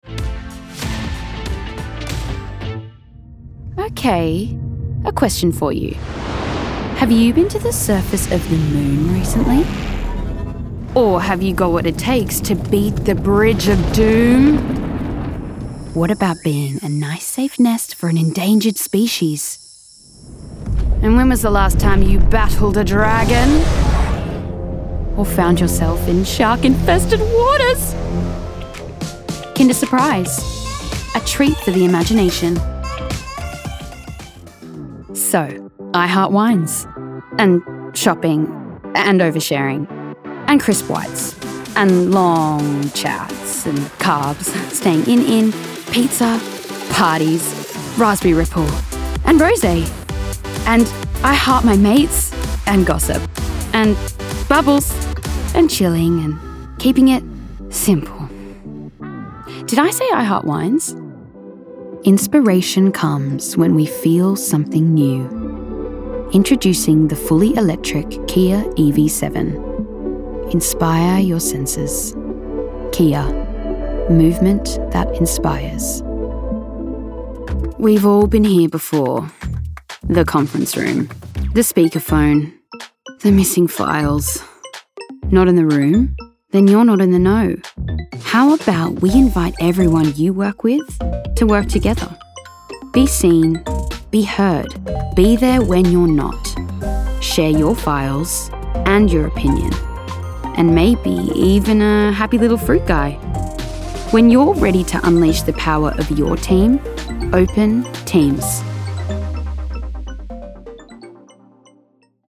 The Voice Realm is your leading directory for professional female voice talent.